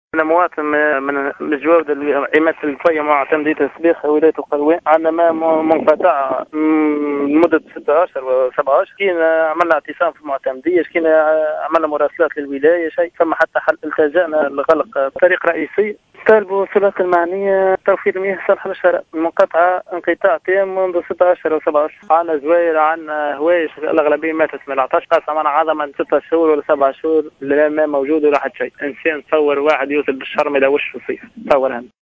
يتواصل انقطاع الماء بمنطقة "الڨفيْ" التابعة لمعتمدية السبيخة من ولاية القيروان منذ أكثر من 6 أشهر، وهو ما أصبح يُهدد حياة الأهالي و الماشية التي نفق عدد منها حسب ما أكدّه أحد المحتجين في تصريح للجوهرة "أف أم".